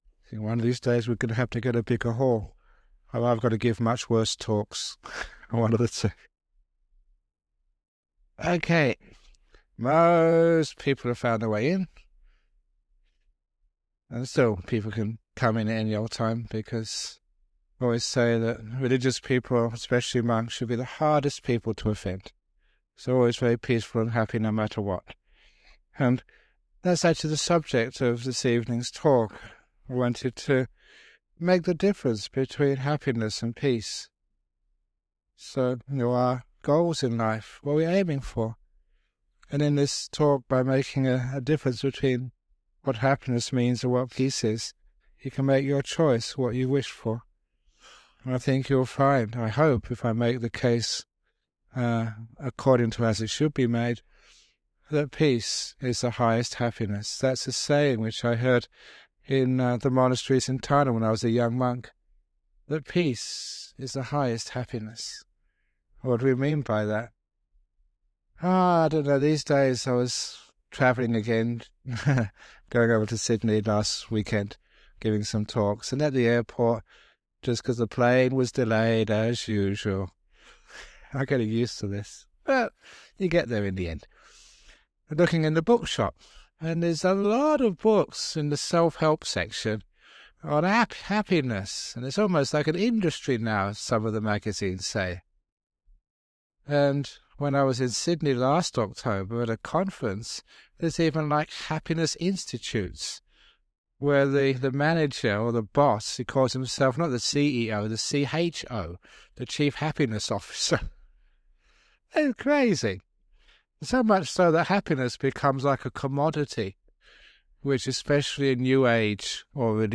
Ajahn Brahm talks about finding peace in the midst of turmoil and imperfection. — This dhamma talk was originally recorded in 2nd May 2008. It has now been remastered and published by the Everyday Dhamma Network , and will be of interest to his many fans.